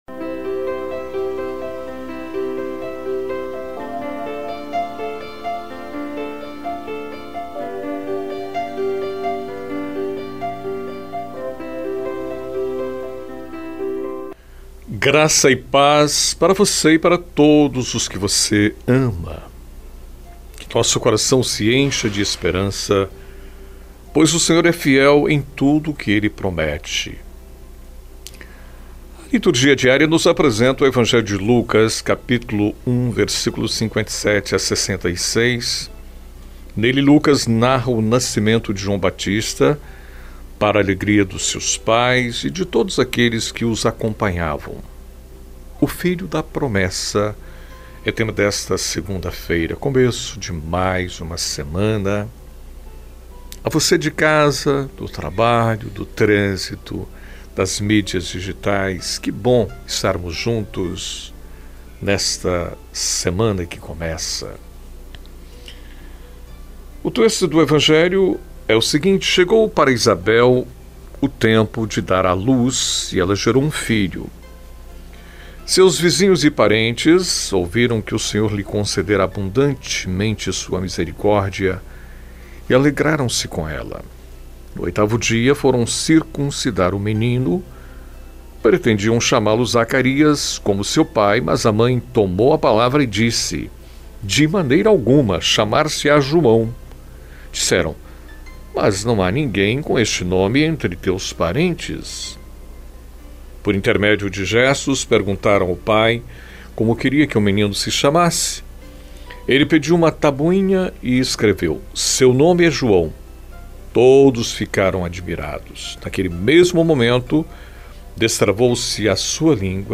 É um momento de reflexão diário com duração de aproximadamente 5 minutos, refletindo o evangelho do dia, indo ao ar de segunda a sexta, na voz do locutor